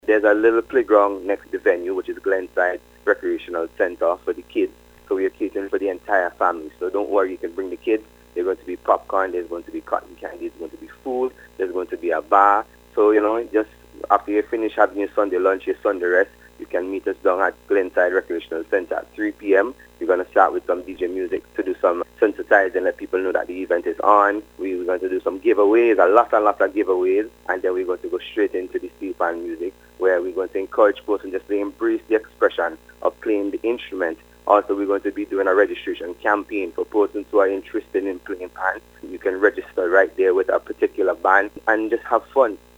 interview with NBC News